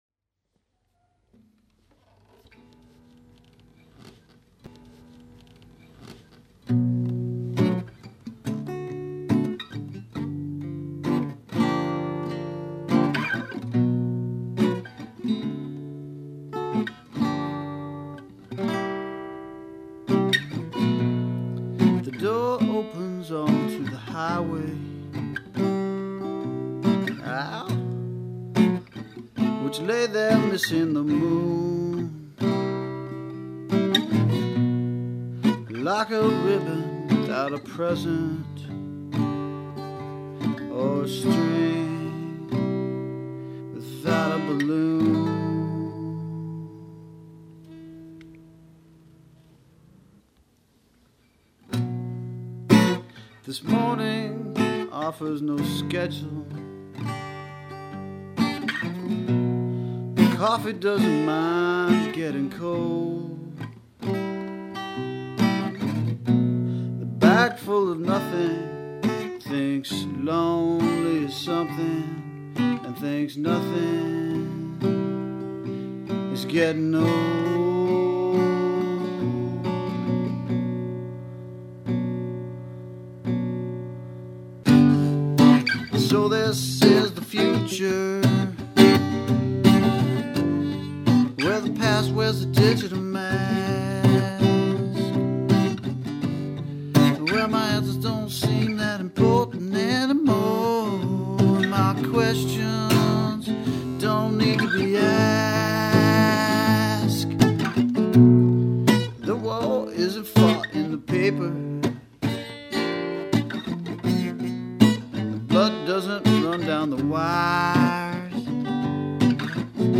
In Venice Beach, California.
This might have been recorded on a Sony Mini-Disc with a Sony Stereo Mic.
Acoustic something.